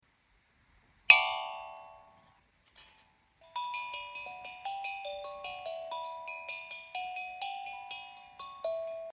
This and heavy rain 😍🌧